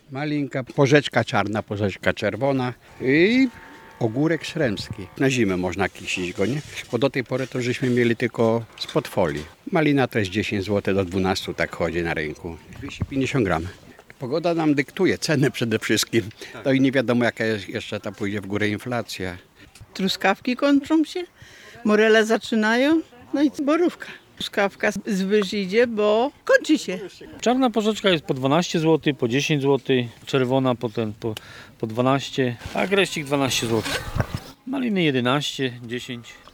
Na targowiskach pojawiają się owoce sezonowe, ceny są o około 30 procent wyższe niż w ubiegłym roku ale kupujących nie brakuje. Sprzedawców z targowiska przy ulicy Jasnej w Szczecinie zapytaliśmy o aktualne ceny.